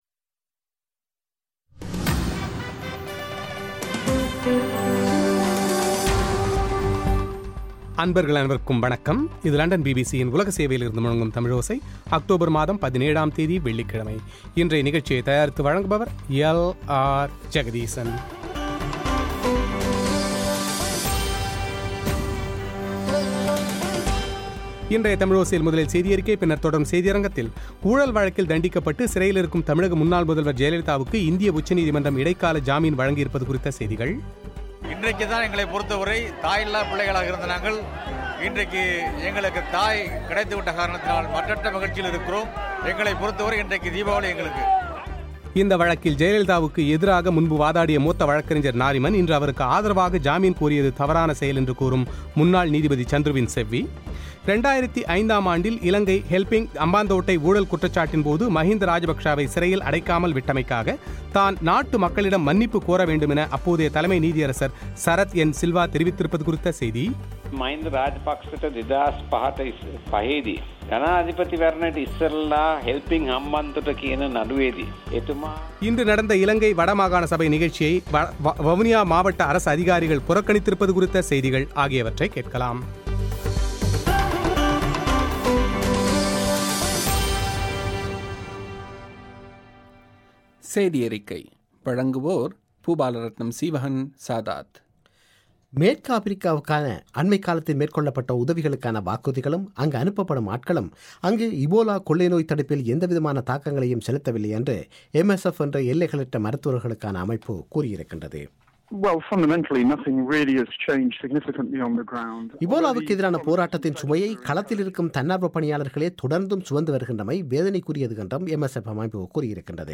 ஊழல் வழக்கில் தண்டிக்கப்பட்டு சிறையில் இருக்கும் தமிழக முன்னாள் முதல்வர் ஜெயலலிதாவுக்கு இந்திய உச்சநீதிமன்றம் இடைக்கால ஜாமீன் வழங்கியிருப்பது குறித்த செய்திகள்;